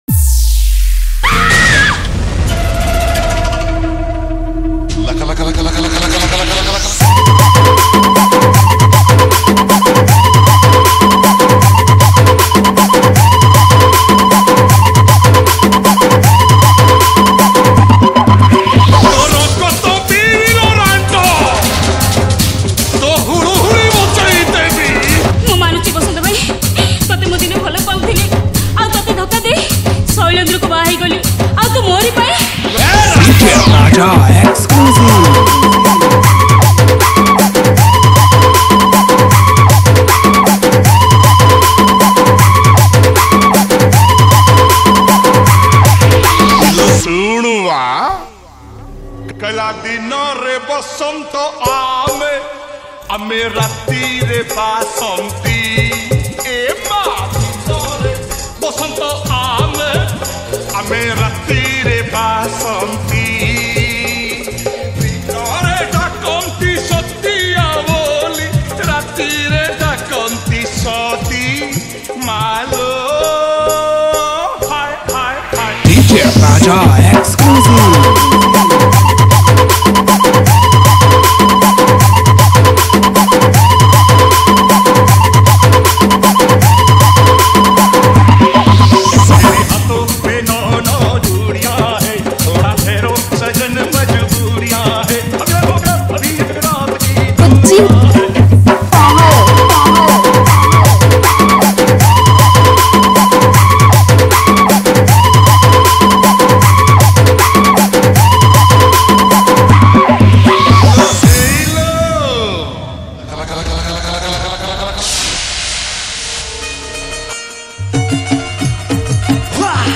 Hulahuli & Nagin Music Collection